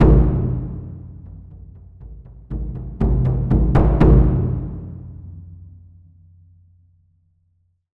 その響きを再現するために、TAIKO THUNDERはスタジオではなくホールでサンプリング収録を行いました。
• St.Mix：マルチマイク音源をバランス良くミックスしたサウンド（ステレオ）です。